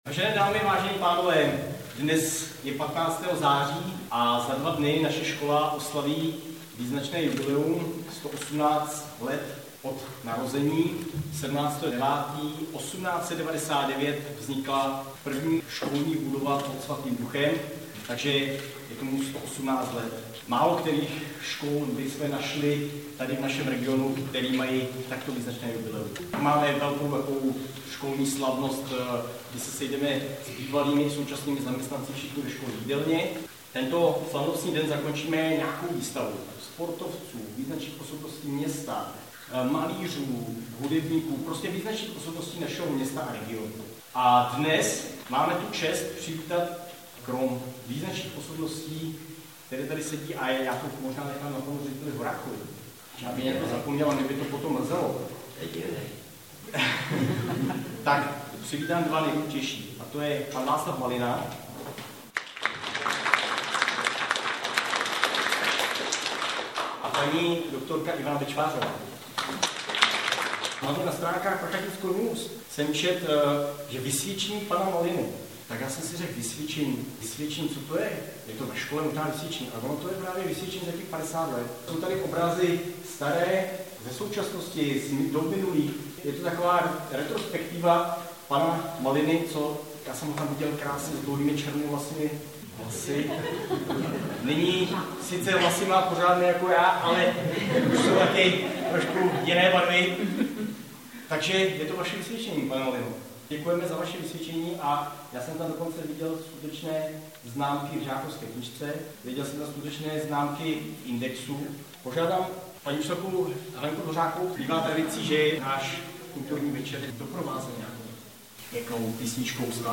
Reportáž z vernisáže si můžete poslechnout zde.